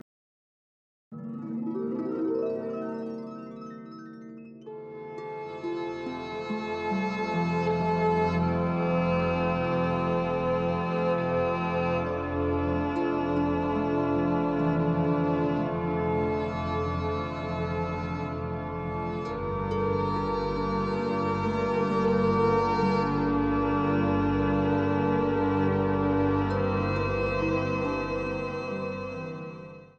Heilmusik für Trost und Halt in schwierigen Zeiten